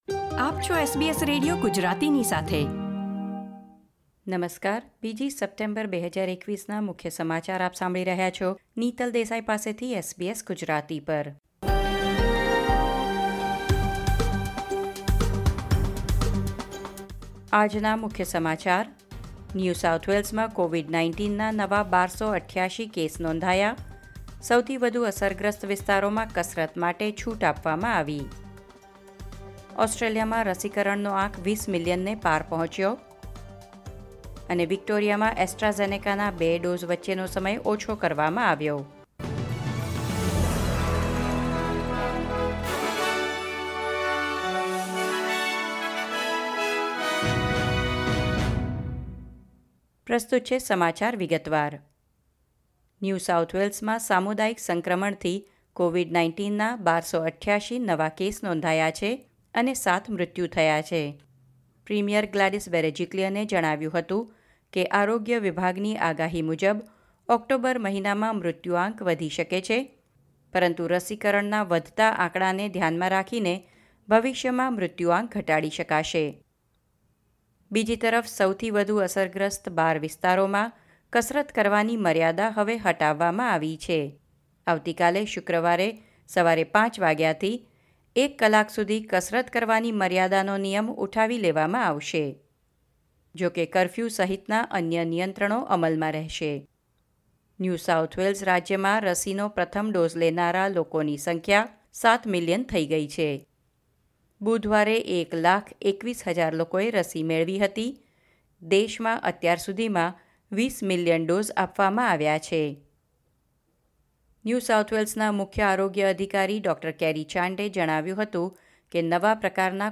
SBS Gujarati News Bulletin 2 September 2021